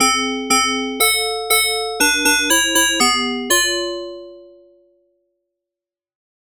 PM is often used to produce metallic, bell-like sounds. Here's a sequence of notes using such an instrument. The instrument uses 2 PM synth carrier-modulator pairs, plus an extra oscillator to add a very slight bassy thump for each note.
bell0.mp3